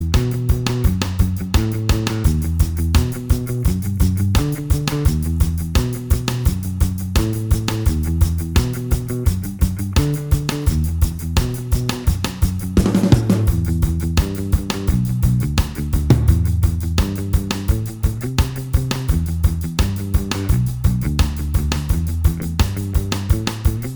Minus All Guitars Ska 3:06 Buy £1.50